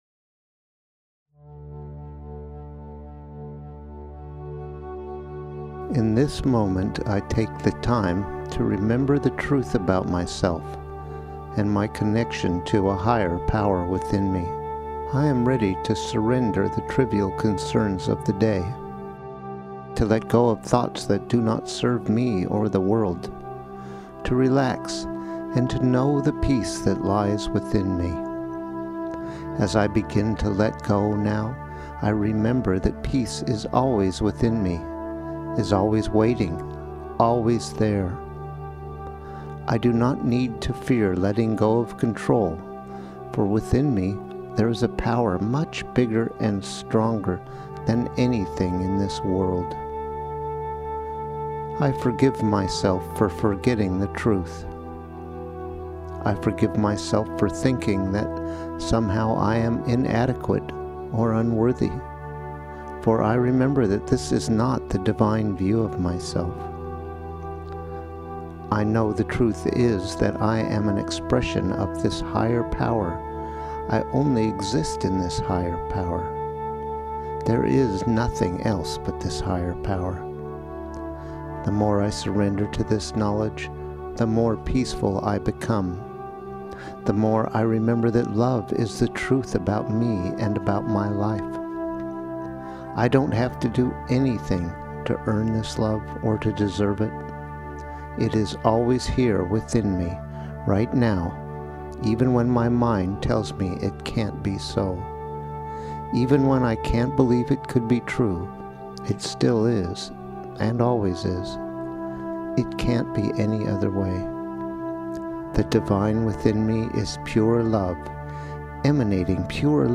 This is a good overall prayer/treatment for remembering the truth